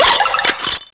Cri de Sorbouboul dans Pokémon Noir et Blanc.